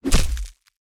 attack.mp3